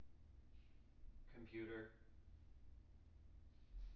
wake-word
tng-computer-181.wav